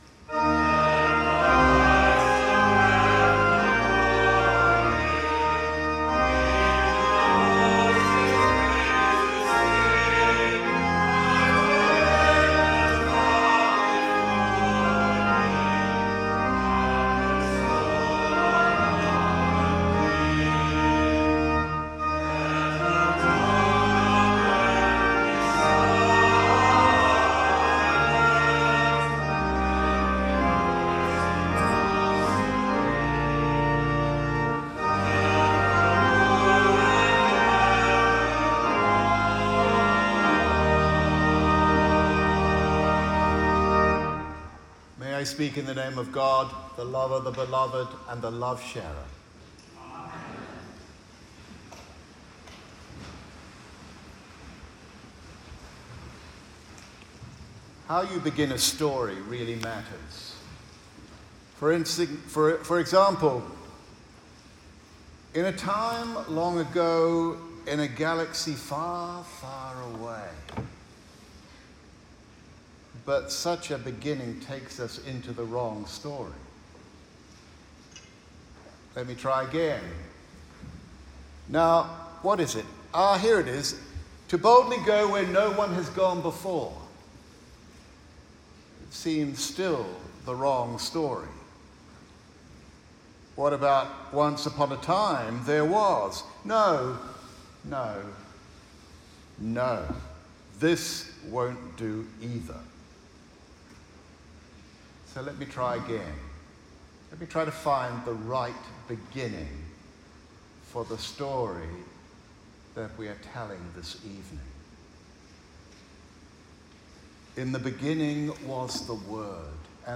Audio: Note the recording is a version of the text below, streamlined for oral delivery